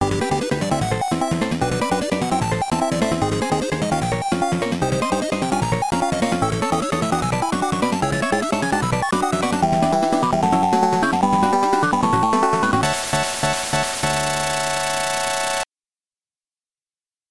This music was recorded using the game's sound test.